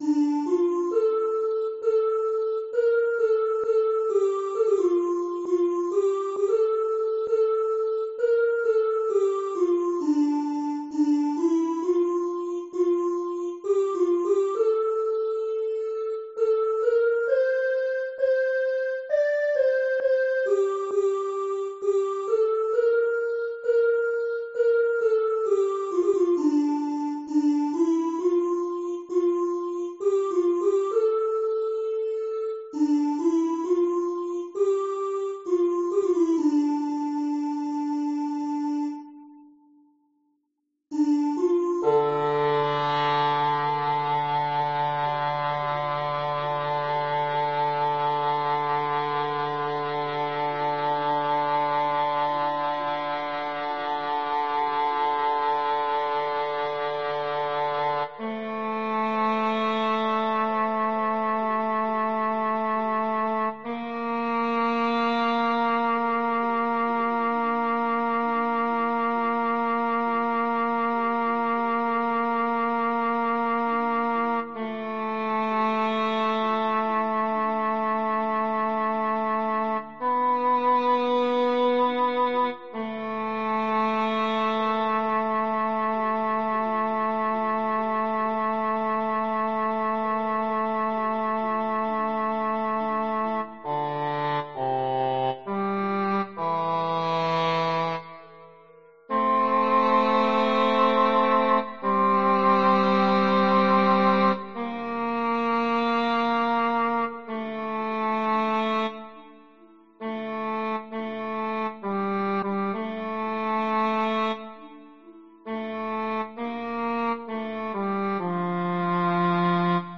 - Hymne du soir en norvégien